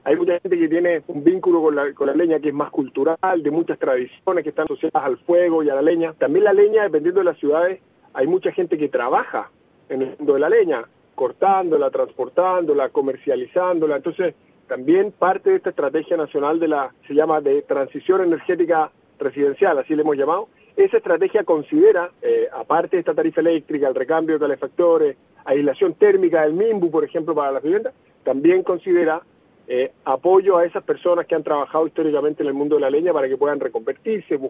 En conversación con La Radio, el ministro de Energía afirmó que lo que se busca es entregar una alternativa de calefacción, eso sí, a través de equipos eléctricos con menores niveles de contaminación.